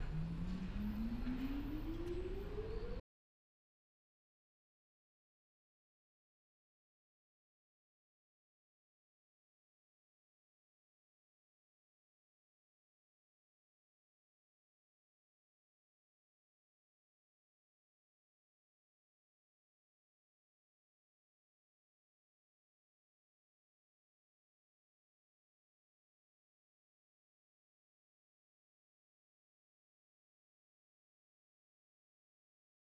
examples01p01_STI-Noise.wav